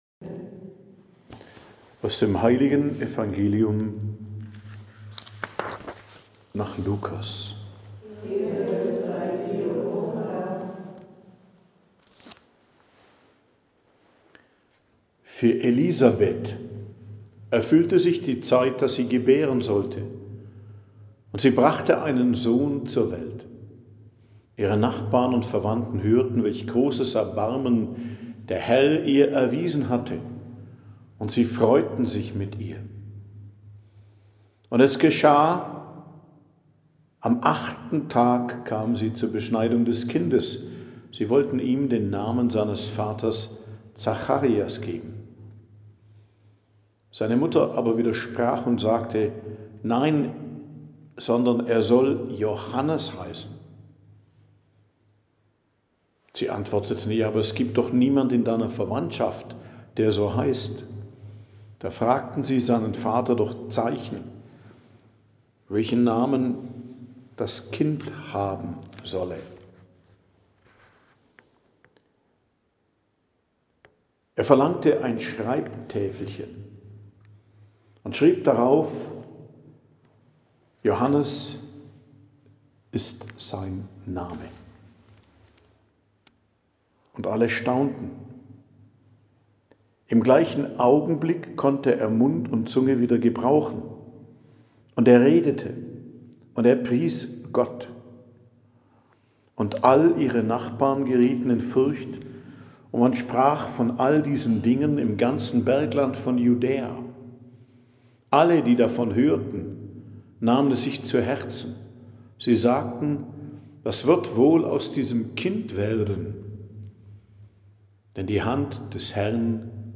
Predigt am Dienstag der 4. Woche im Advent, 23.12.2025 ~ Geistliches Zentrum Kloster Heiligkreuztal Podcast